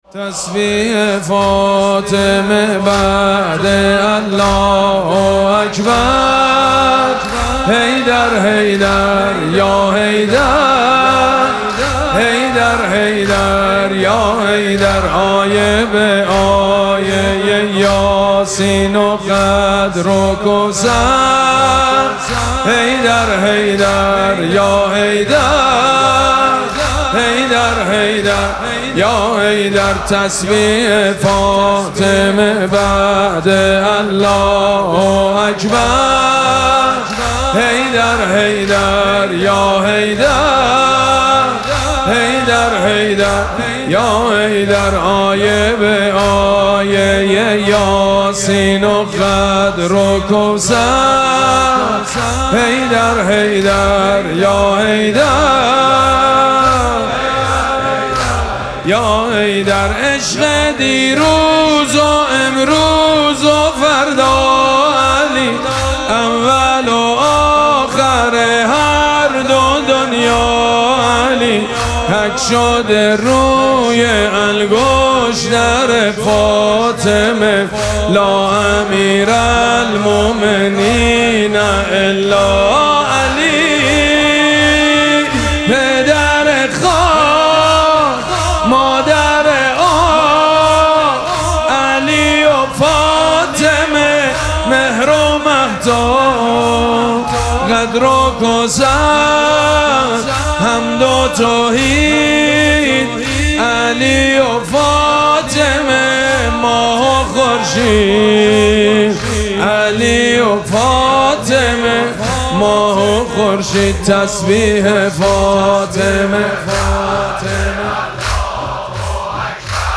مراسم مناجات شب بیست و یکم ماه مبارک رمضان
حاج سید مجید بنی فاطمه